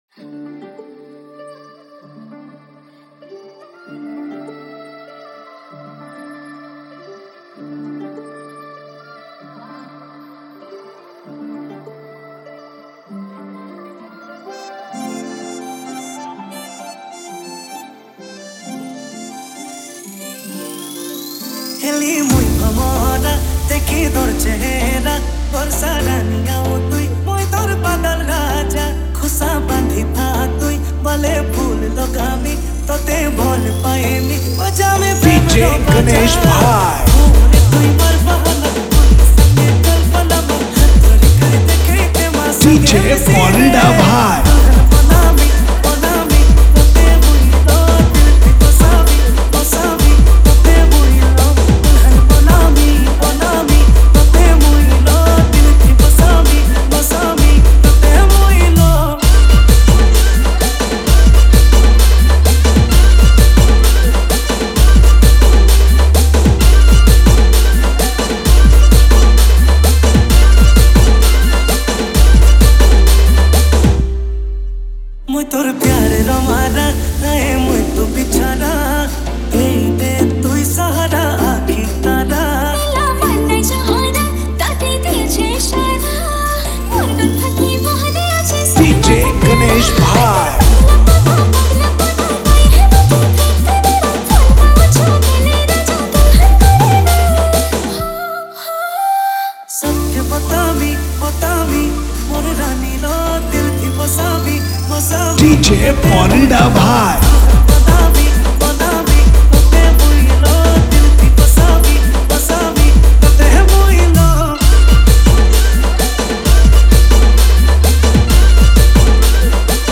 - odia dj song